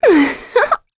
Talisha-Vox_Happy1.wav